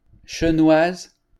Chenoise (French pronunciation: [ʃənwaz]